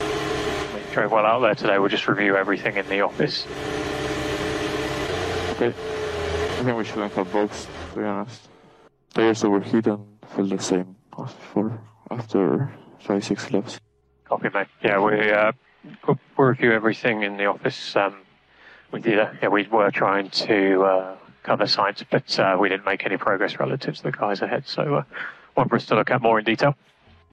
“¡No tendríamos que haber parado!”, lanzó Franco por radio, visiblemente caliente, tras una parada en boxes que le complicó aún más un domingo para el olvido.